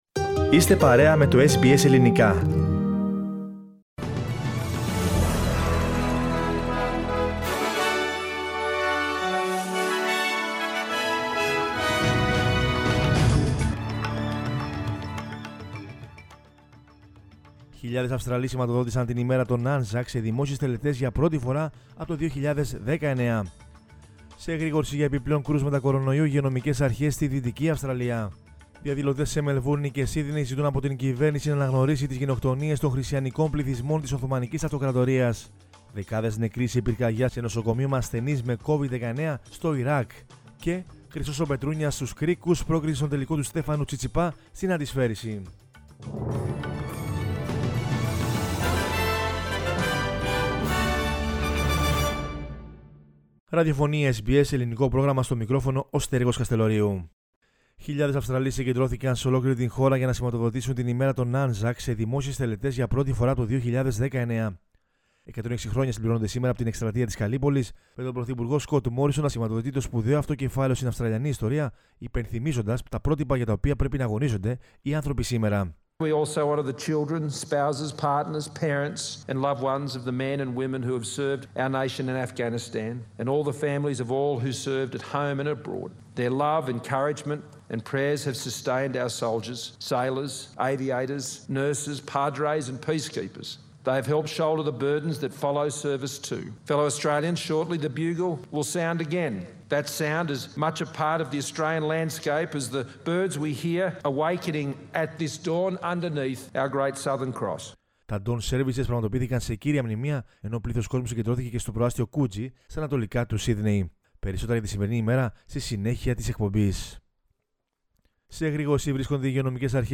News in Greek from Australia, Greece, Cyprus and the world is the news bulletin of Sunday 25 April 2021.